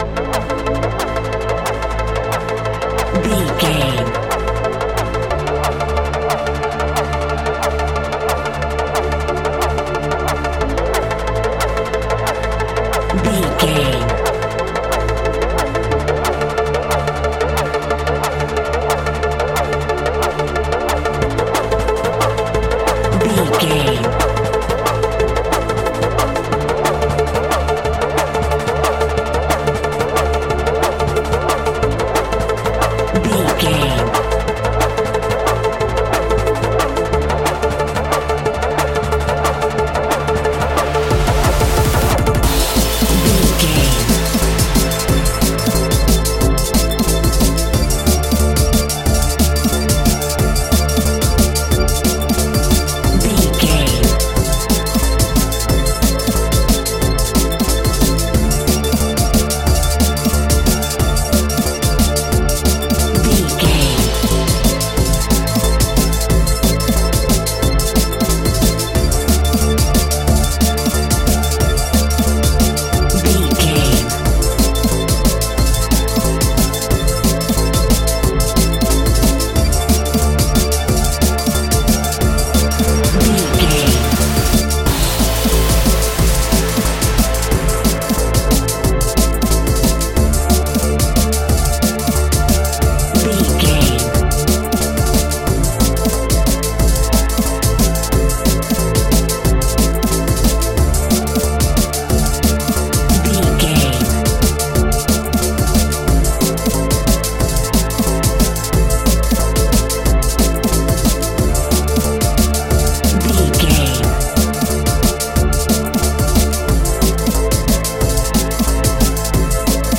Aeolian/Minor
Fast
aggressive
dark
futuristic
industrial
frantic
synthesiser
drum machine
sub bass
synth leads